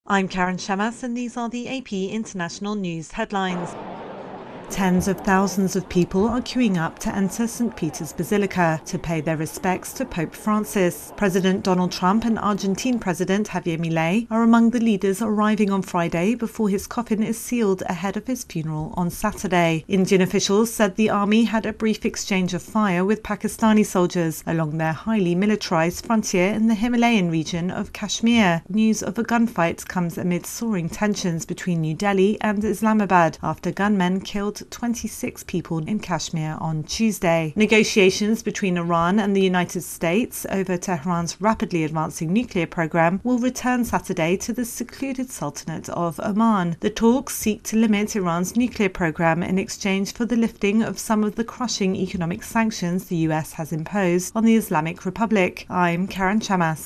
The latest international headlines